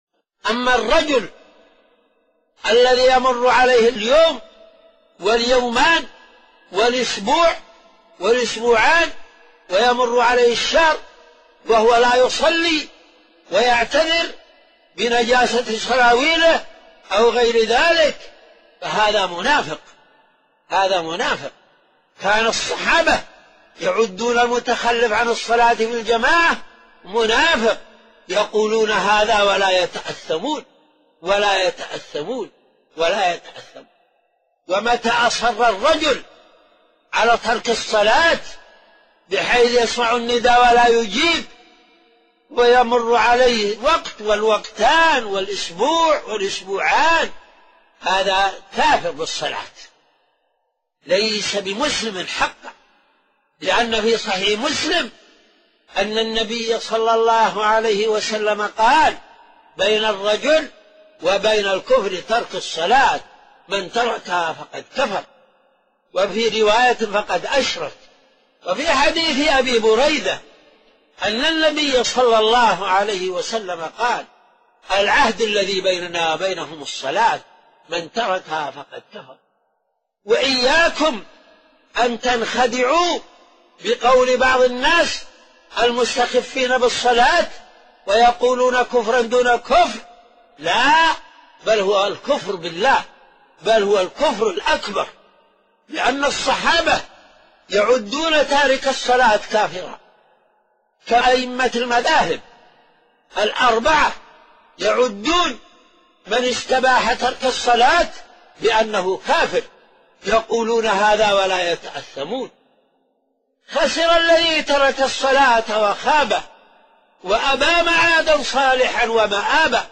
خطب الجمعة (صوتي) - خطب الجمعة (صوتي) - 8: حكم تارك الصلاة - الشيخ عبدالله بن زيد آل محمود